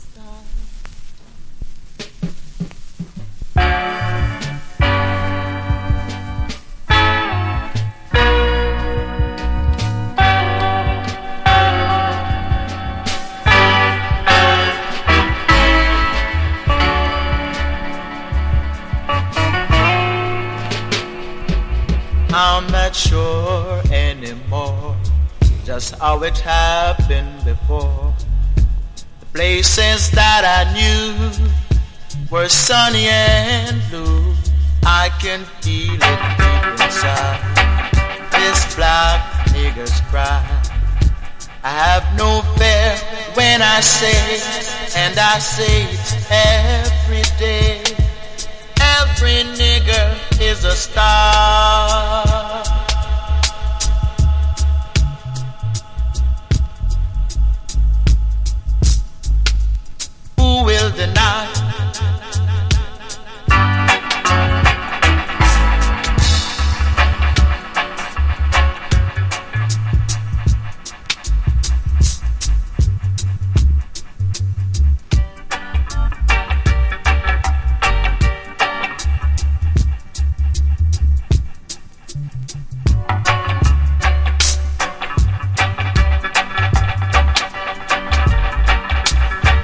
• REGGAE-SKA
# ROOTS# DUB / UK DUB / NEW ROOTS